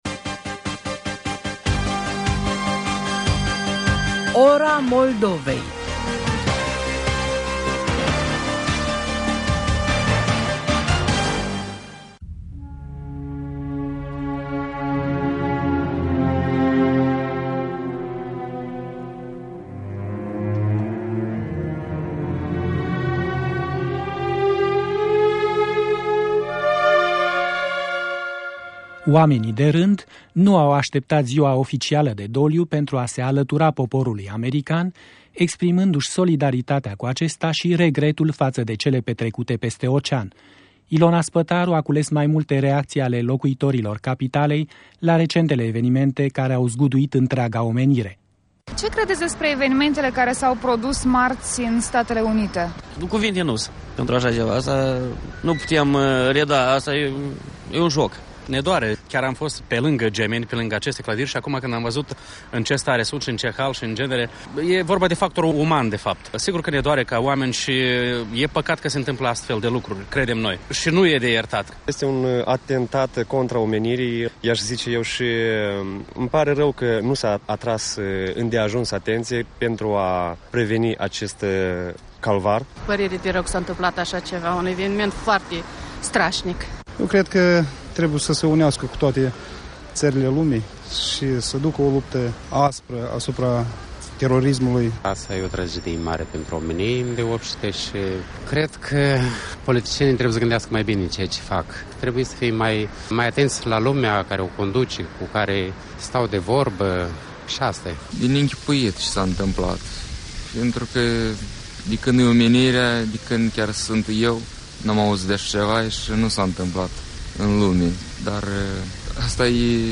Reacții ale străzii la Chișinău la tragedia americană